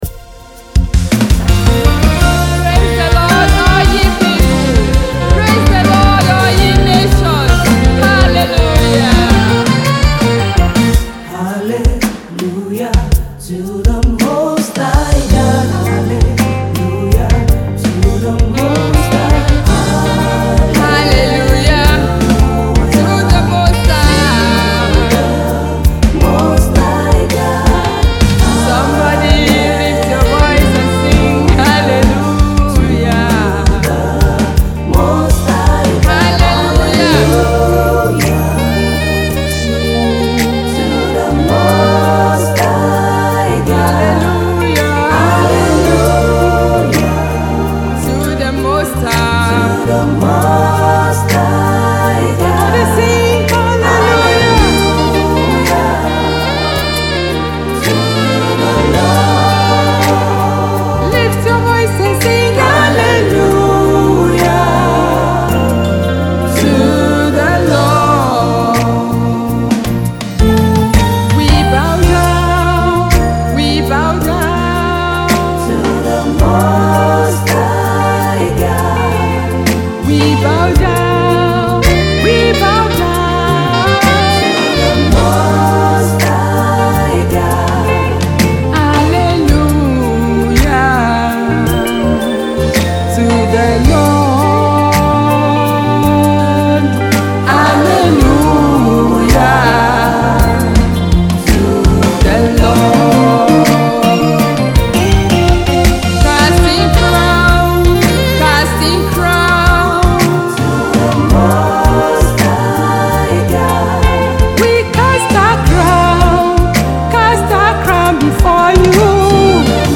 vibrant energy and unique musical expressions